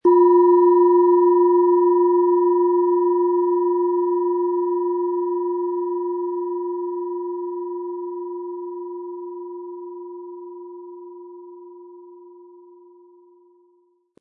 Planetenschale® Nach oben öffnend & Weg in die Trance gehen mit Platonisches Jahr, Ø 10,6 cm inkl. Klöppel
Sie sehen eine Planetenklangschale Platonisches Jahr, die in alter Tradition aus Bronze von Hand getrieben worden ist.
Der kräftige Klang und die außergewöhnliche Klangschwingung der traditionellen Herstellung würden uns jedoch fehlen.